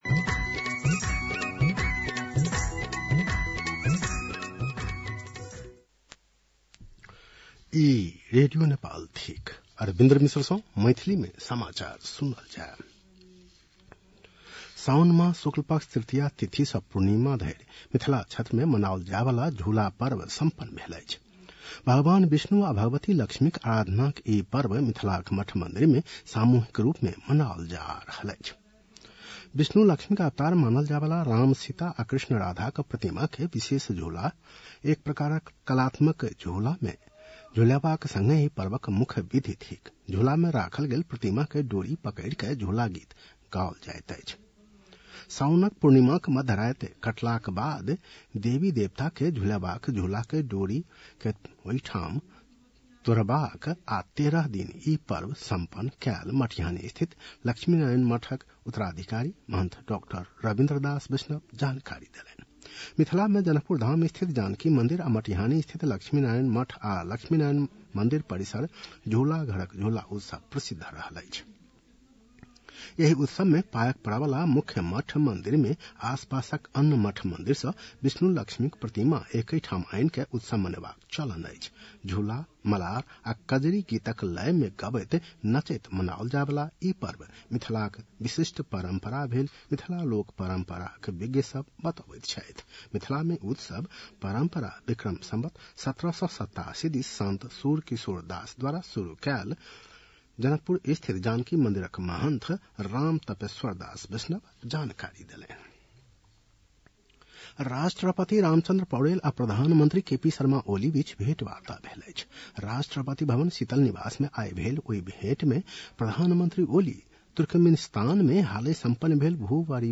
An online outlet of Nepal's national radio broadcaster
मैथिली भाषामा समाचार : २५ साउन , २०८२